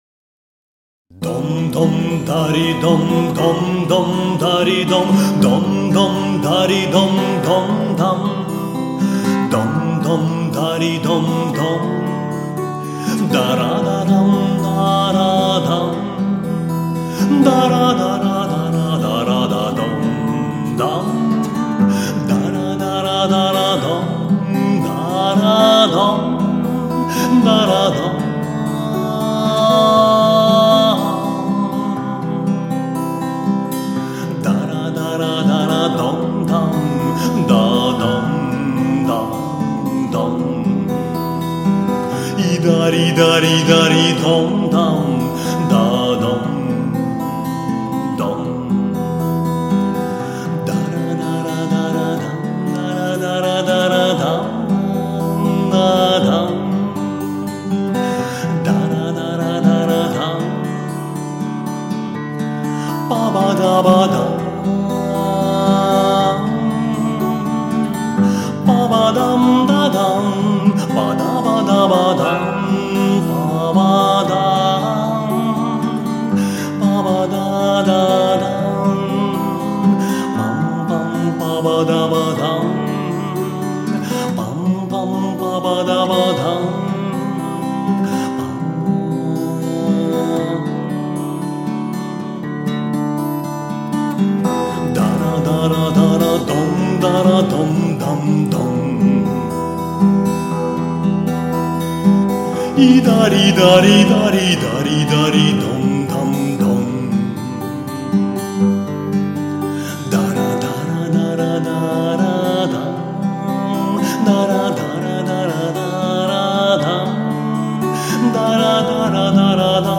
арт - классик - бард